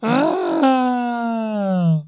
描述：Todos los sonidos vocales hechos por los personajes secundarios del corto